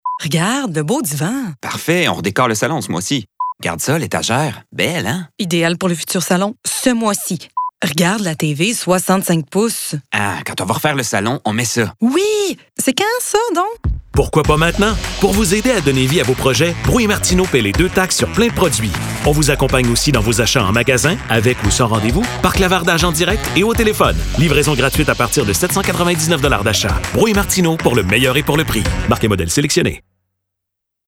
Publicity - Voix 1